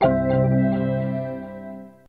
Mac OS 20th Anniversary Startup [20th Anniversary Macintosh].mp3